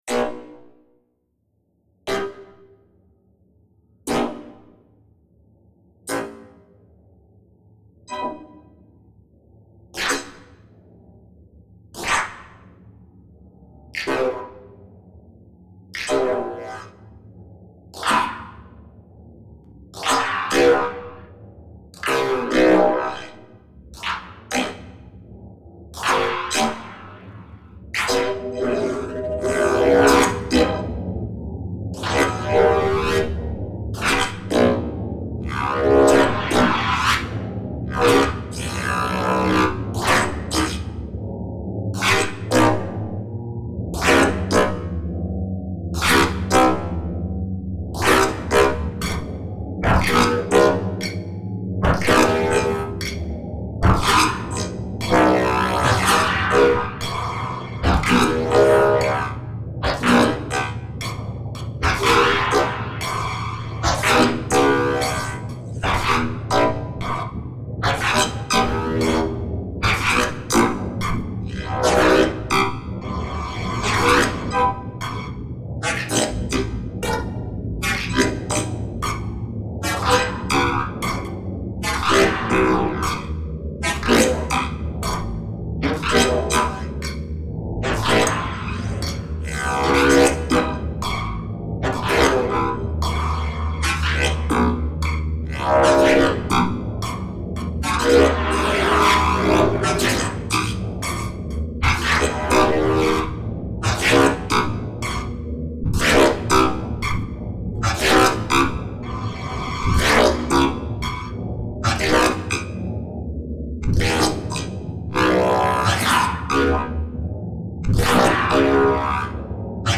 De facto, its role is to sharply subdivide in a relatively constant fluid the wide steeped chattered knocks we obsessively repeated for days, in the attempt to synchronize the 4 of us in one secure unity.